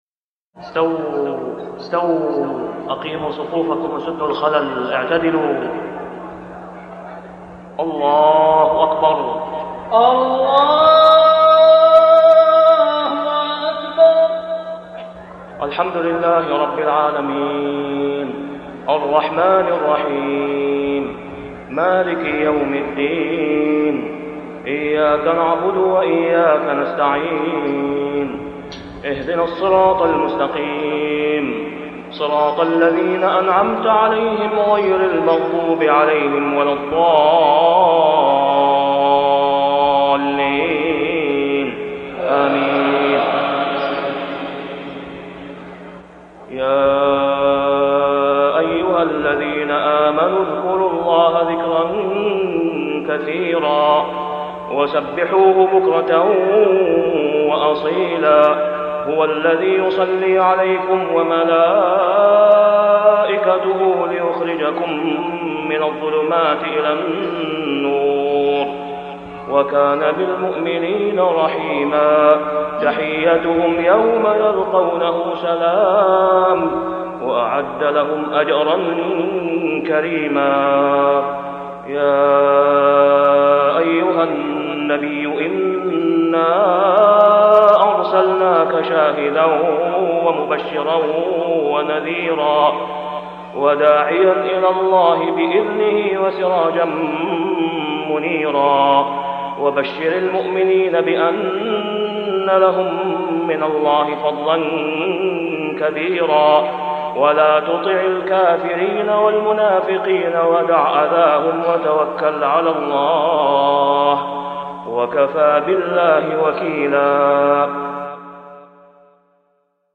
( صلاة العشاء و العام غير معروف ) | سورة الأحزاب 40-48 > 1430 🕋 > الفروض - تلاوات الحرمين